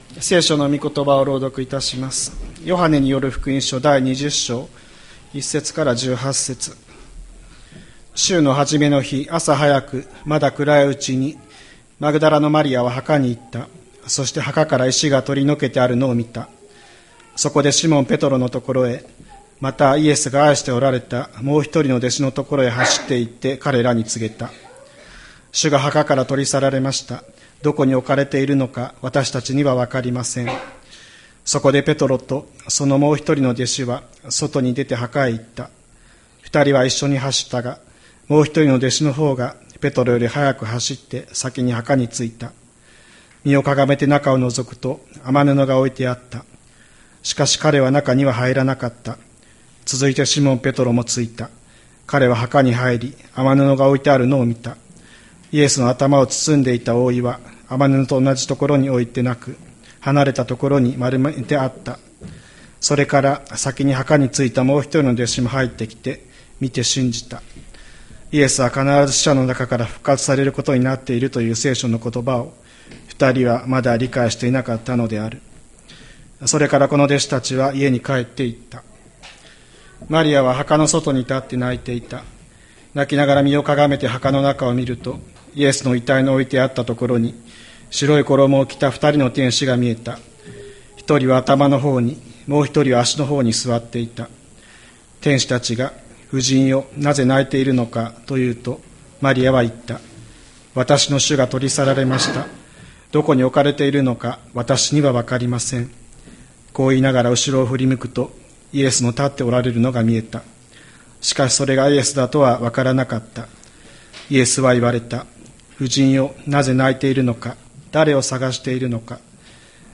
2026年04月05日朝の礼拝「確かないのちへの招き」吹田市千里山のキリスト教会
千里山教会 2026年04月05日の礼拝メッセージ。